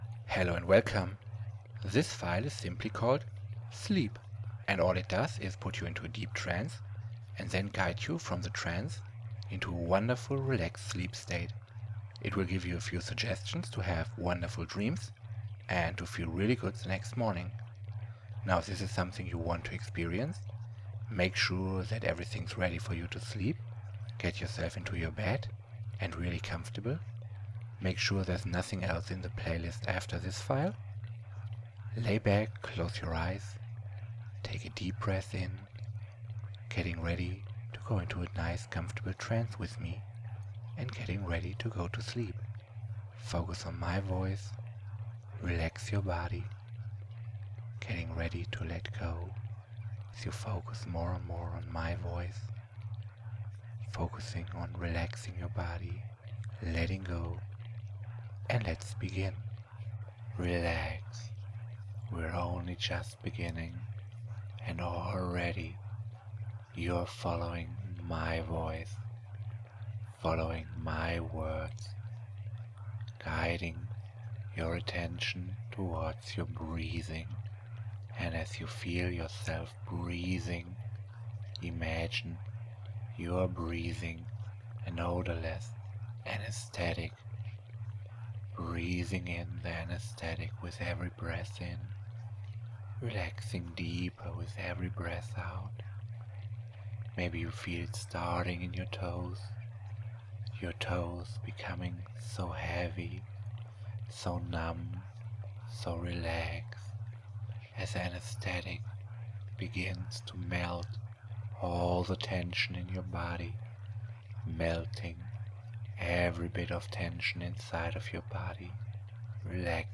Sleep better and feel refreshed with this free hypnosis recording